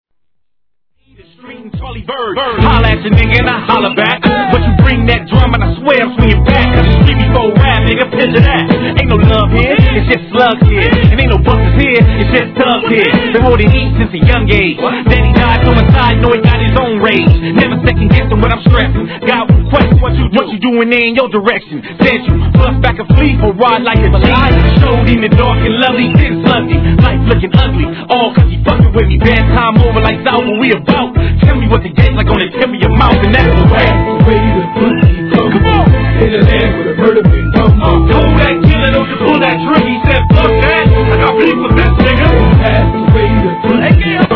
HIP HOP/R&B
WEST COASTからの二人組み!!! ダイナミックなサウンドに女性コーラスが絡むキャッチーな一曲!!